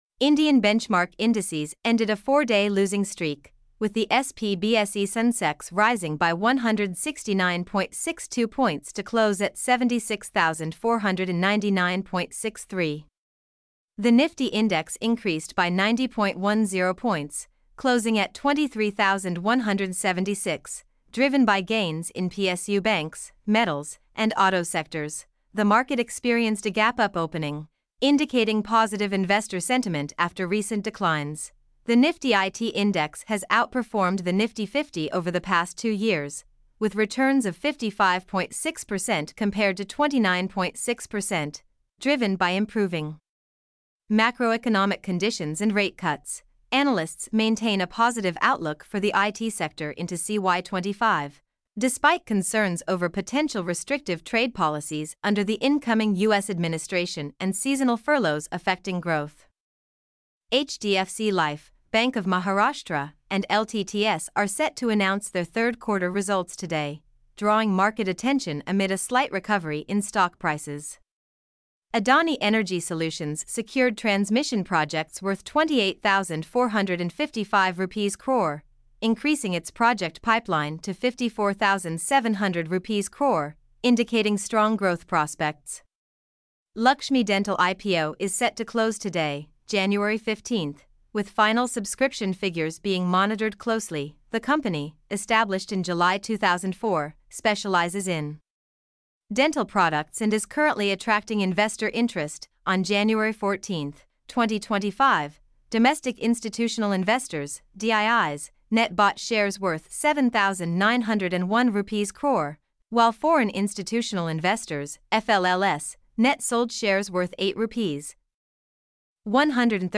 mp3-output-ttsfreedotcom5.mp3